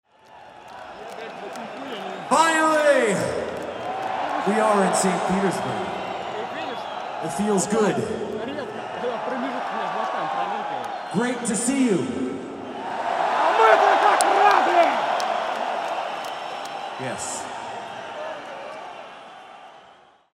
P.P.S.: Забавный момент нарыл в чьих-то аудиозаписях концерта: После исполнения «Ride the lightning» Хэт говорит: «Finally, we all in Saint Petersburg.
А мы-то как рады»!